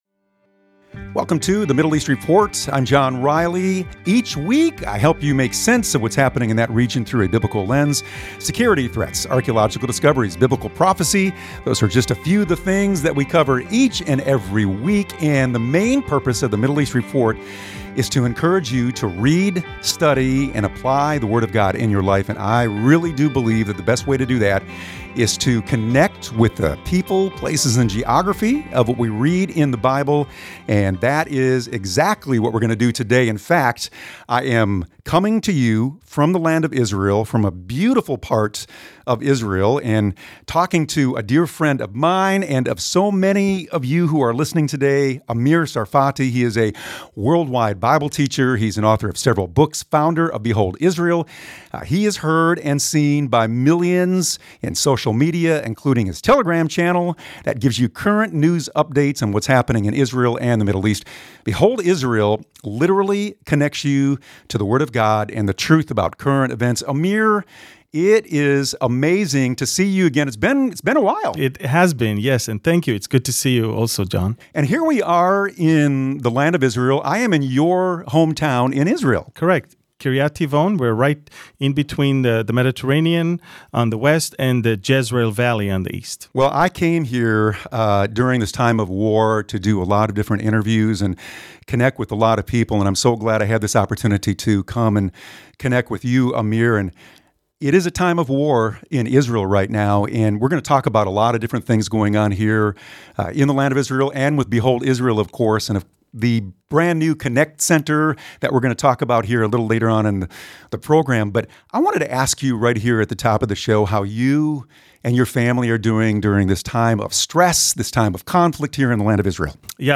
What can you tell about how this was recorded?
Show Notes I am broadcasting from Israel this week interviewing IDF and government officials, including leaders from various ministries.